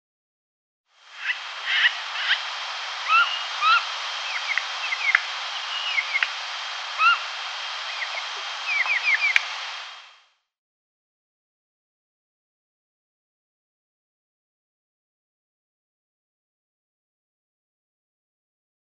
En aquests cants s'amaguen 4 ocells misteriosos, però per facilitar-ho afegeixo una pista per cadascun d'ells